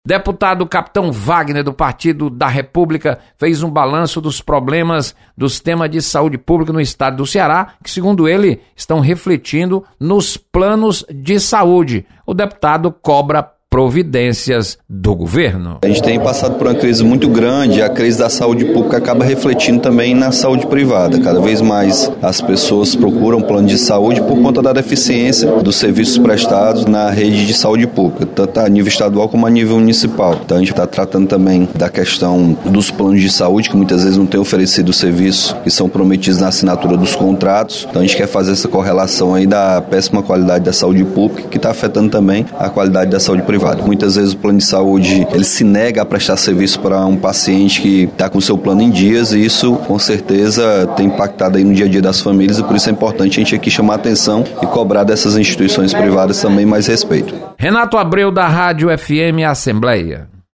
Deputado Capitão Wagner cobra melhoria na qualidade de serviços de saúde. Repórter